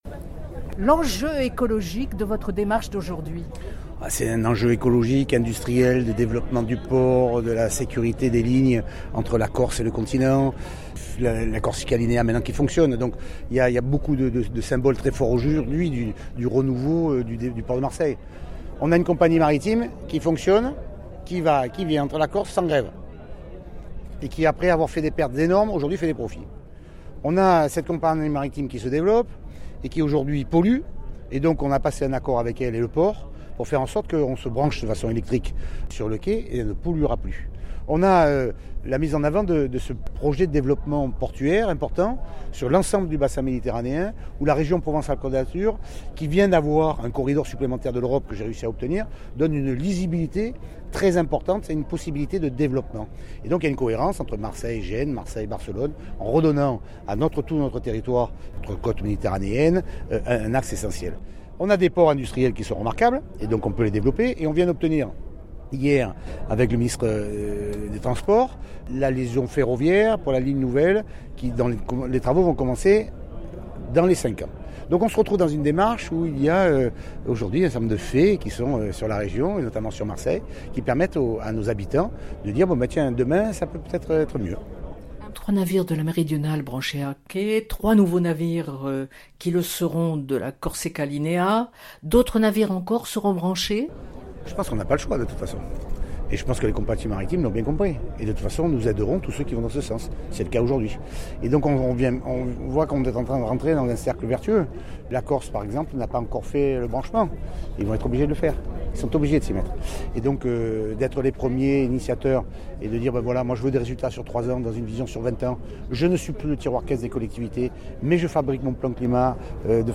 Entretien